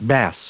Prononciation du mot bass en anglais (fichier audio)